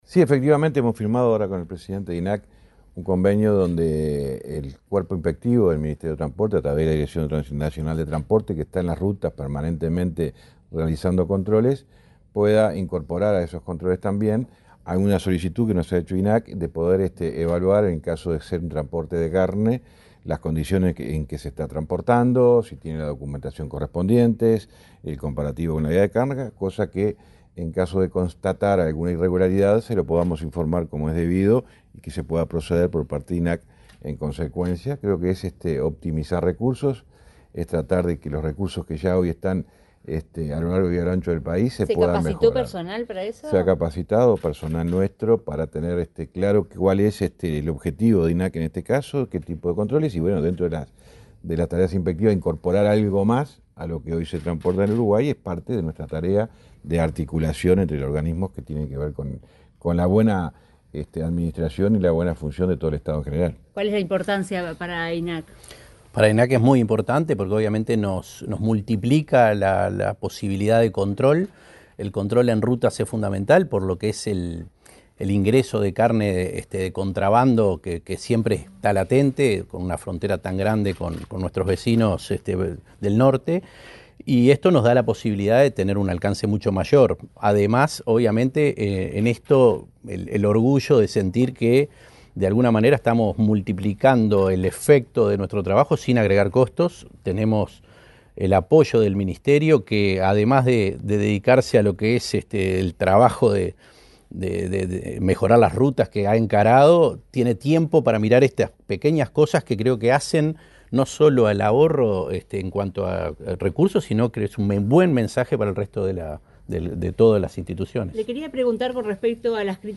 Declaraciones del ministro Falero y el presidente del INAC, Conrado Ferber
El Ministerio de Transporte y Obras Públicas (MTOP) y el Instituto Nacional de Carnes (INAC) firmaron un convenio para reforzar los controles de vehículos que transportan carnes y derivados en el territorio nacional. Luego, el titular de la cartera, José Luis Falero, y el presidente del INAC, Conrado Ferber, dialogaron con la prensa.